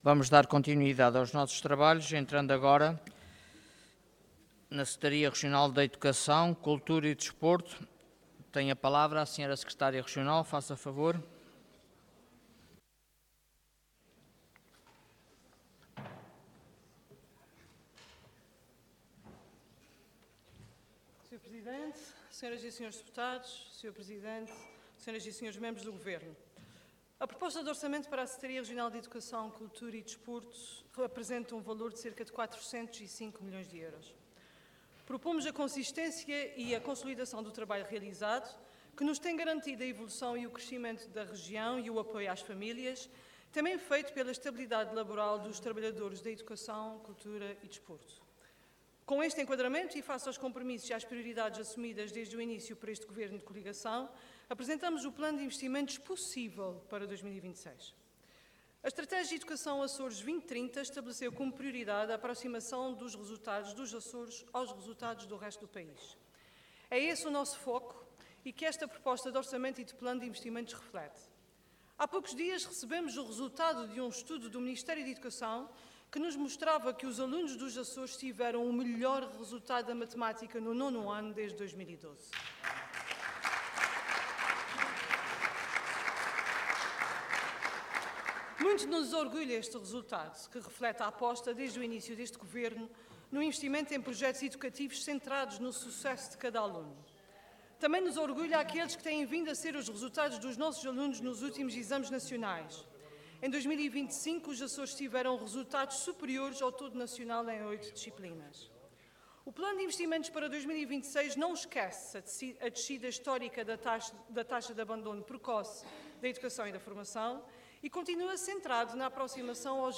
Intervenção de Tribuna Orador Sofia Ribeiro Cargo Secretária Regional da Educação, Cultura e Desporto